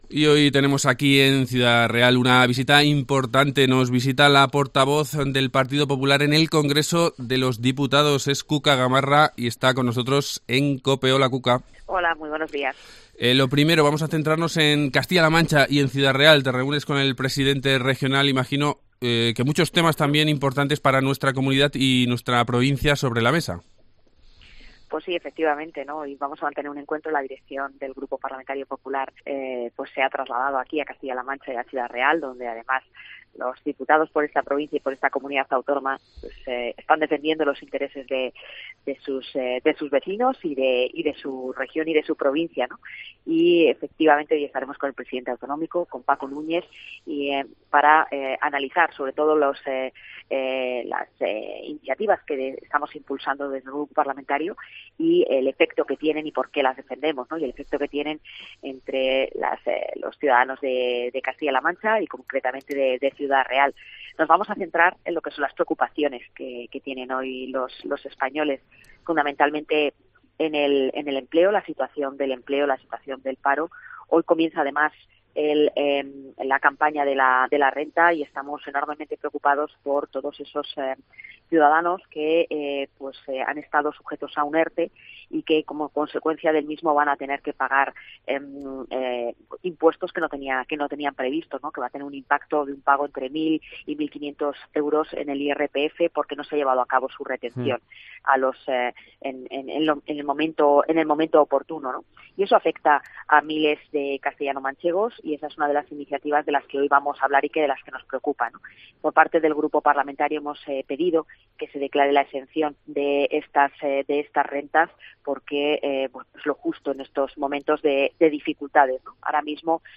Entrevista Cuca Gamarra